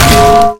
Pan Hit Sound Effect Free Download
Pan Hit